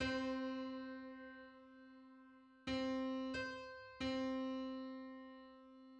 Just: 253/128 = 1179.59 cents.
Public domain Public domain false false This media depicts a musical interval outside of a specific musical context.
Two-hundred-fifty-third_harmonic_on_C.mid.mp3